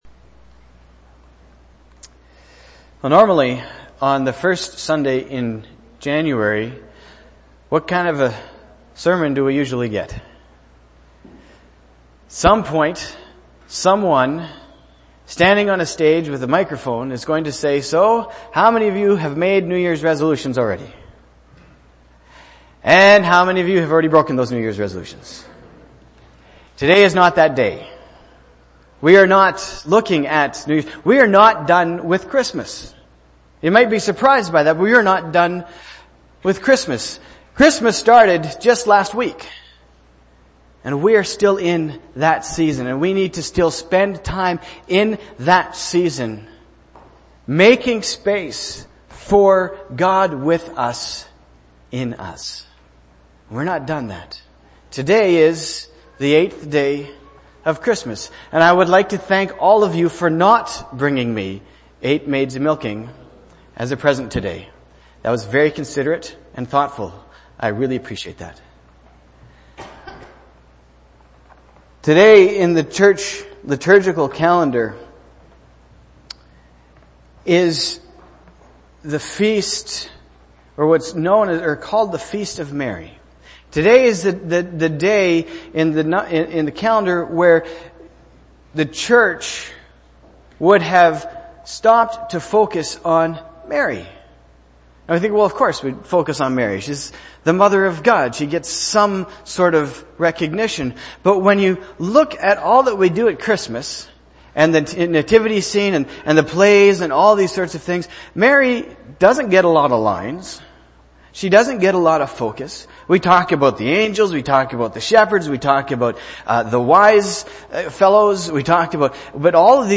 january-1-2017-sermon.mp3